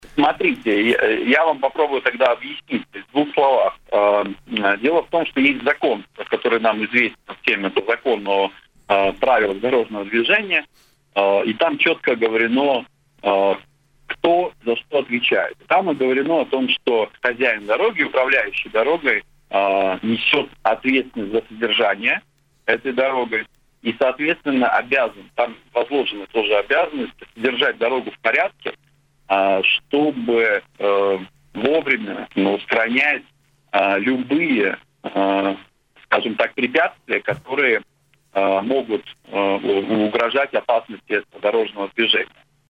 Хозяин дороги несет полную ответственность за ее состояние и должен отвечать за нее перед законом. Об этом в эфире радио Baltkom